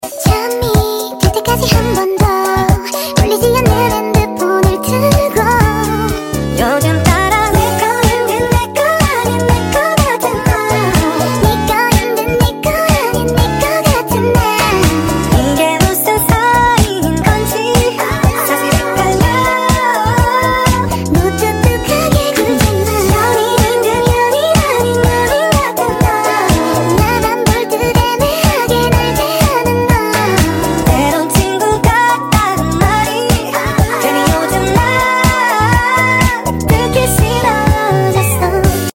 Blue golden British shorthair kittens, sound effects free download